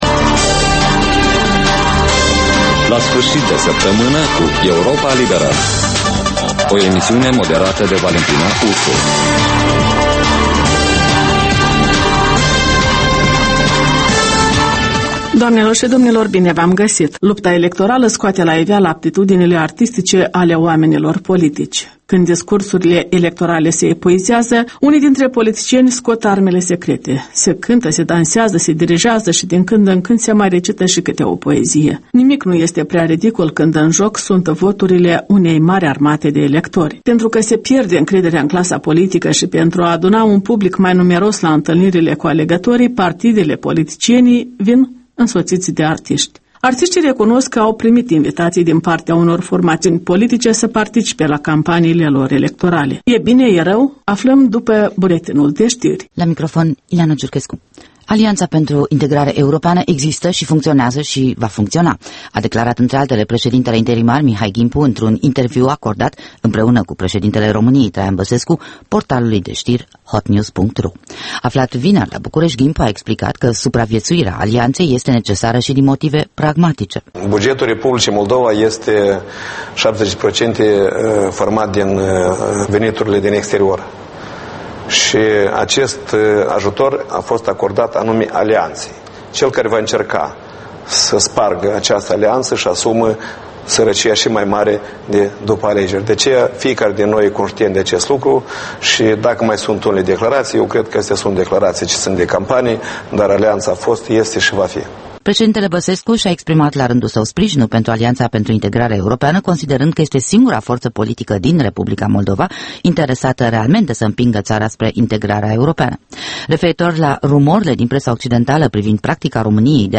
In fiecare sîmbătă, un invitat al Europei Libere semneaza "Jurnalul săptămînal".